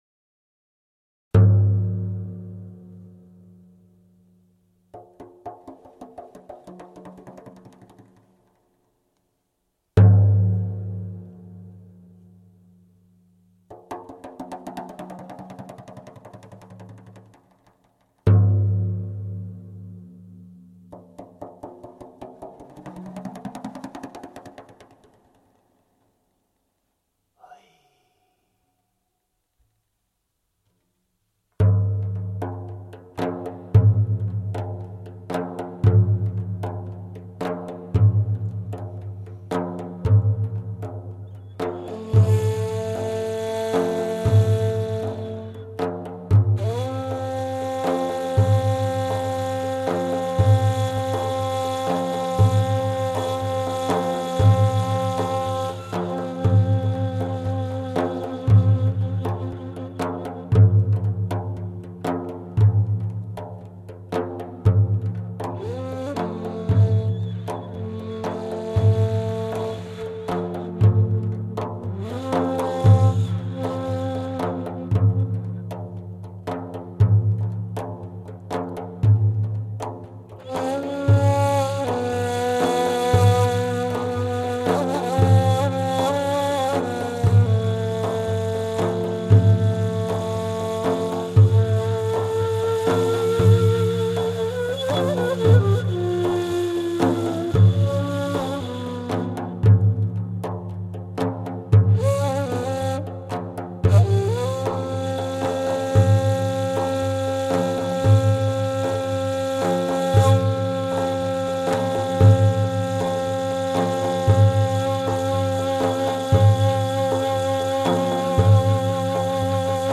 Bendir
Ney
Genellikle 40 ila 55 cm çapında daire şeklindeki bir kasnağa deri germek suretiyle imal edilen bendirin belirgin bir özelliği, rezonans sağlamak amacıyla iç tarafına gerilen iplerdir.
bendir_1.mp3